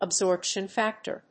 • (米国発音) IPA(key): /æbˈsɔɹp.ʃn̩ ˈfæk.tɚ/, /æbˈzɔɹp.ʃn̩ ˈfæk.tɚ/, /əbˈzɔɹp.ʃn̩ ˈfæk.tɚ/, /əbˈsɔɹp.ʃn̩ ˈfæk.tɚ/